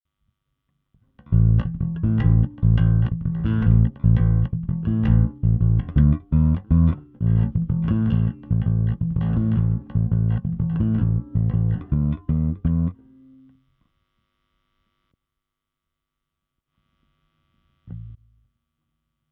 Also am Ende der Aufnahme hört man dann die Nebengeräsche besser. Mal mit Komp an, mal aus.
Edith: Das ist kein Rauschen, sondern mehr ein hochfrequentes Brummen. Also was systematisch suboptimales Anhänge oe_nebengeraesche.mp3 755,1 KB